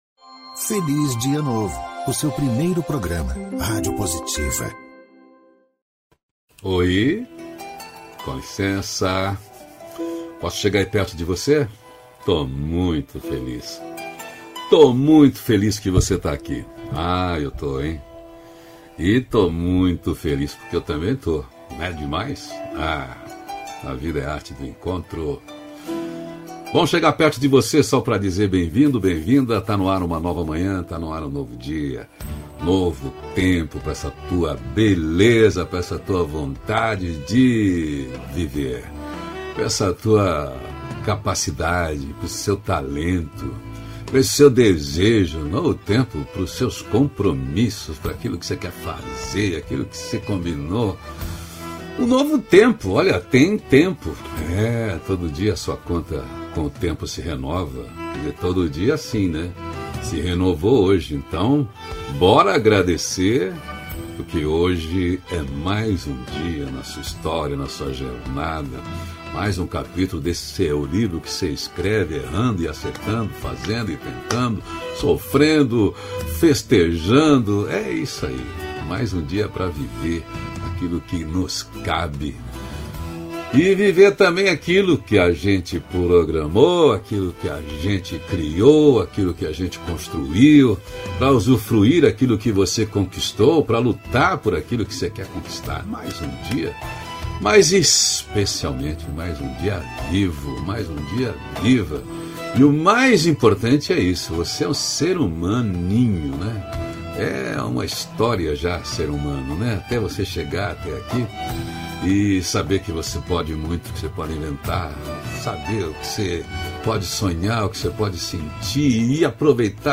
Hoje No programa que tem o papo mais nutritivo do rádio e da internet * Audio book-: Insight- ao vivo- […]